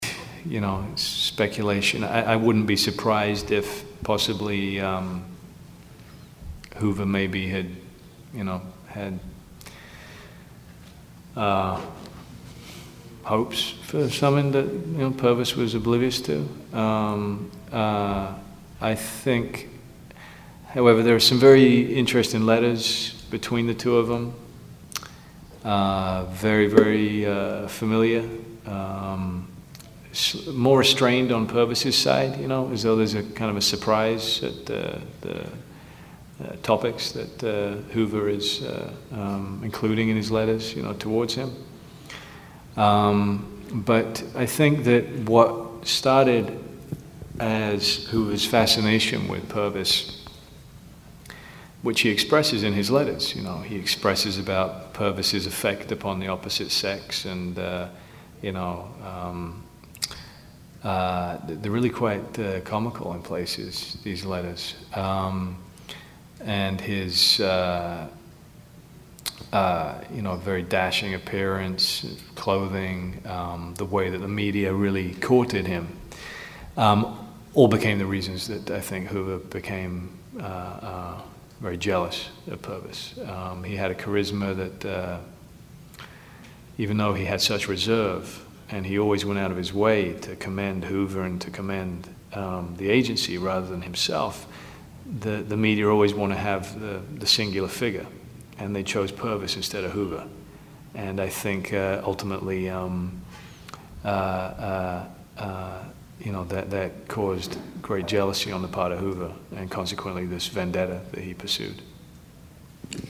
Public Enemies press junket